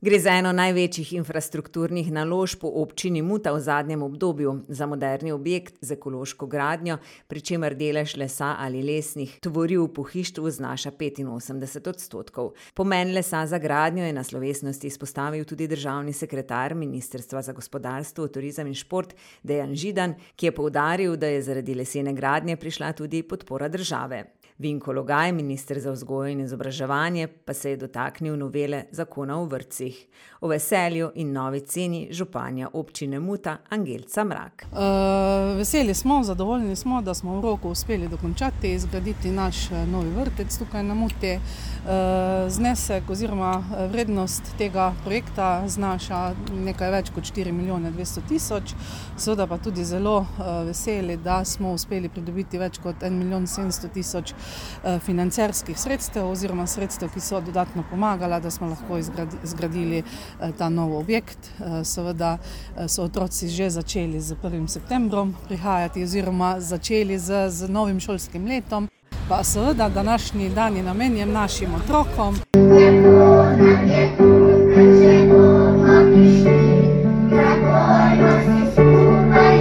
Pa še malo veselega vzdušja s prireditve, ki so naredili najmlajši: